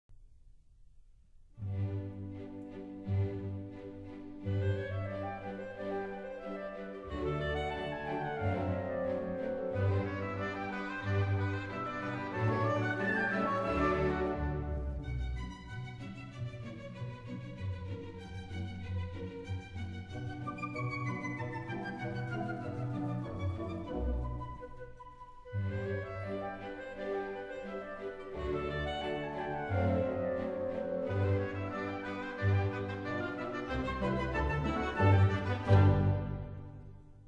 Allegretto
稍快板<00:37>
Sorry, 论坛限制只许上传10兆一下，而且现在激动的网速巨慢，所以只能牺牲品质，为大家介绍一下音乐了。